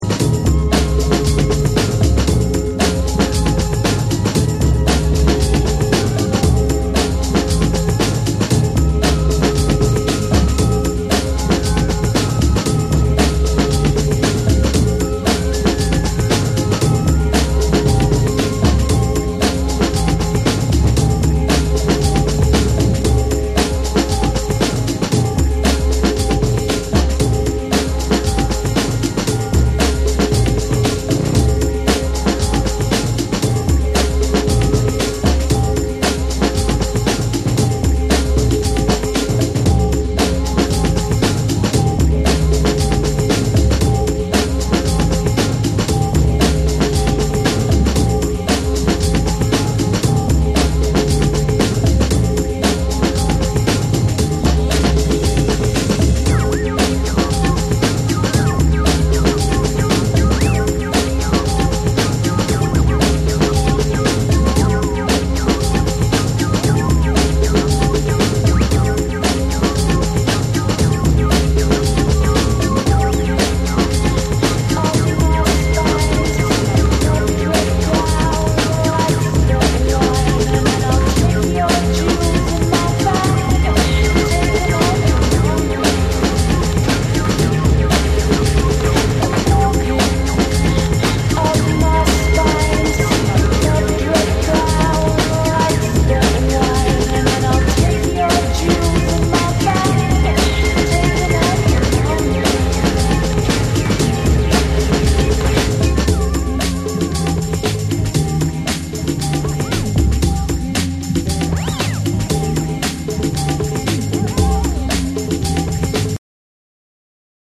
オリジナルのダークでムーディーなトリップ・ホップをよりエレクトロニックに、またはビート重視のアプローチで再構築！
BREAKBEATS